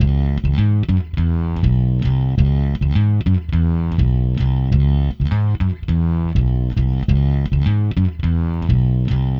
Ala Brzl 1 Fnky Bass-C#.wav